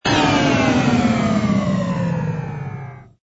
engine_no_cruise_stop.wav